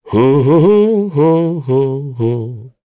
Esker-Vox_Hum.wav